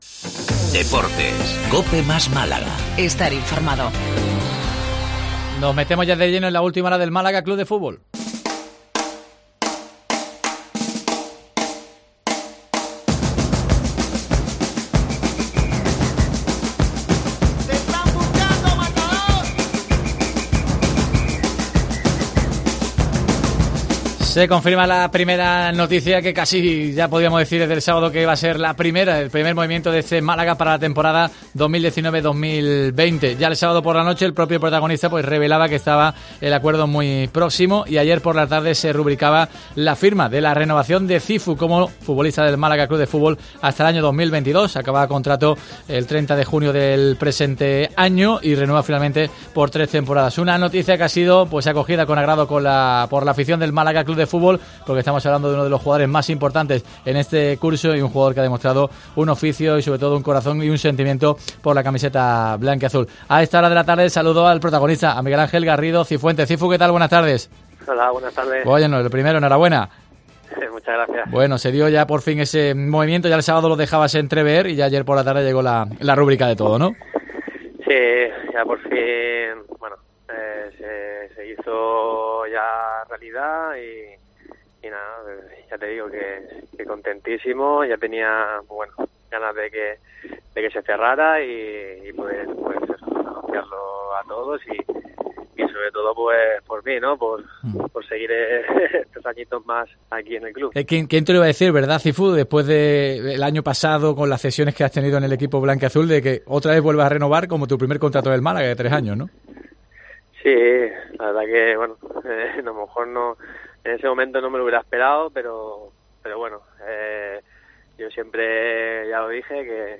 Cifu ha renovado su compromiso con el Málaga hasta 2022 y mostró su satisfacción en los micrófonos de Deportes COPE Málaga.